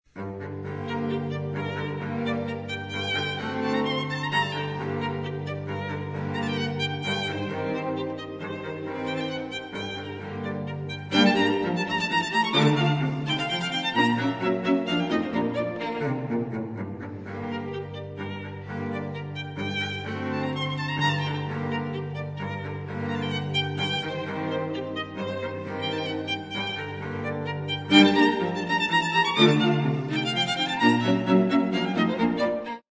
dal Quartetto N.1 in Mi bem. maggiore